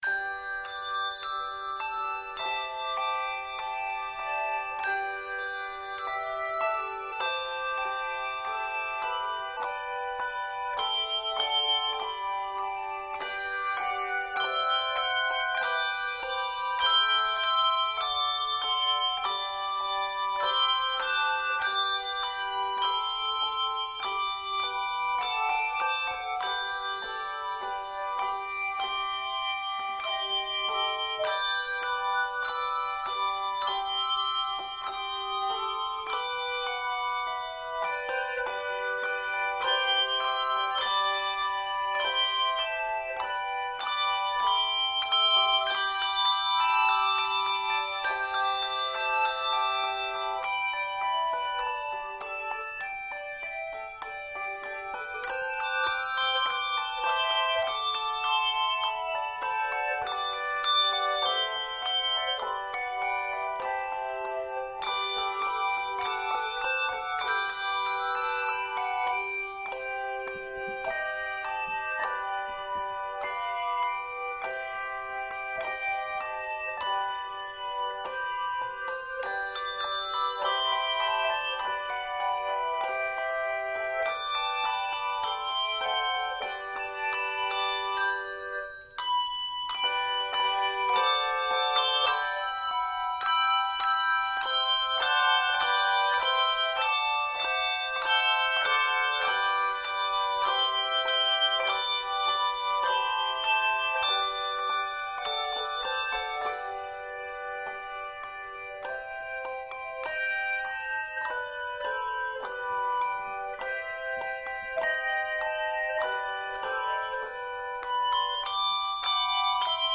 Scored in G Major, it is 56 measures.